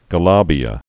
(gə-läbē-ə)